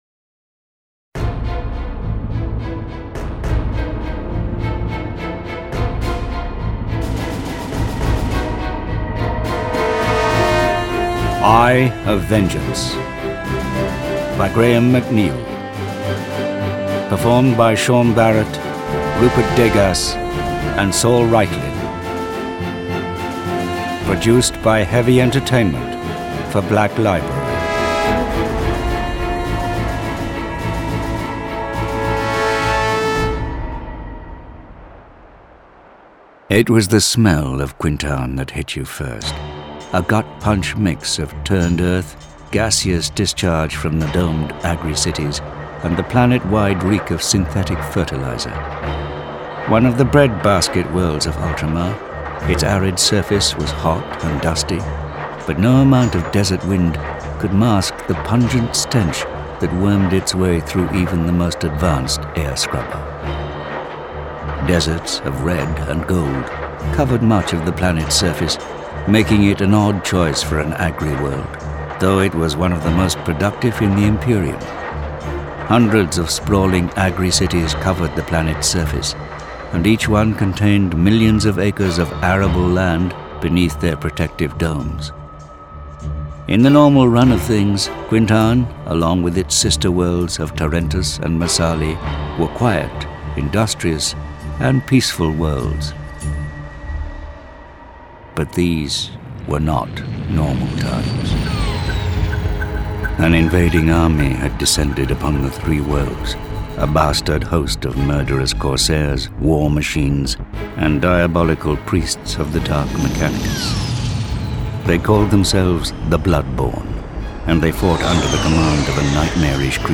Index of /Games/MothTrove/Black Library/Warhammer 40,000/Audiobooks/Eye of Vengeance